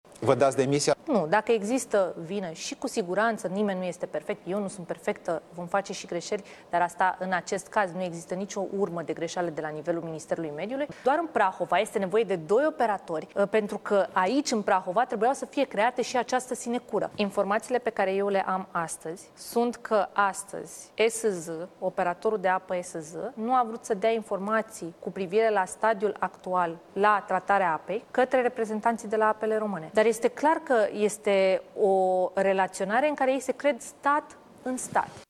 Ministrul Mediului, Diana Buzoianu, a declarat, într-o intervenție la Digi24, că nu intenționează să demisioneze, deoarece nu se consideră responsabilă pentru criza apei de la barajul Paltinu, motivând că instituția pe care o conduce nu a fost informată și prevenită. De asemenea, ea a afirmat că operatorul de apă ESZ nu a dorit să furnizeze informații despre stadiul actual al tratării apei către reprezentanții de la Apele Române.